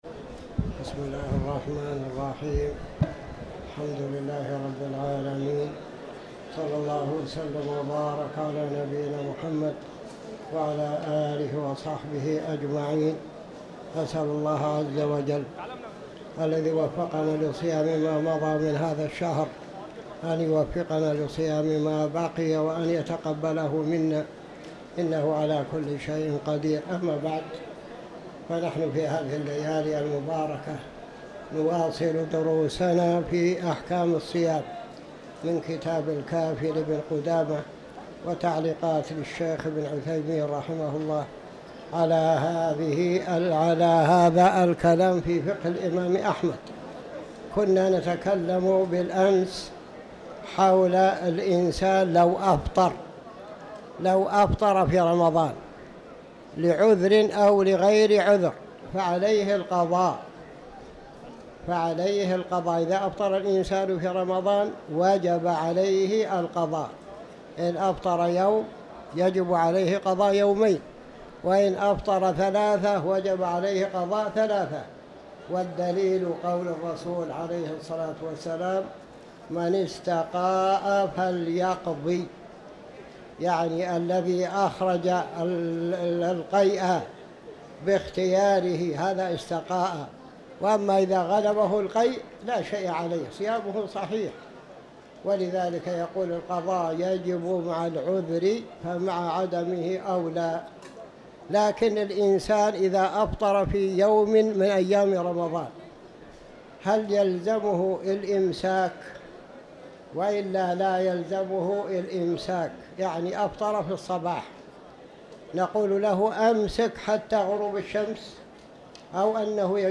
تاريخ النشر ١٦ رمضان ١٤٤٠ هـ المكان: المسجد الحرام الشيخ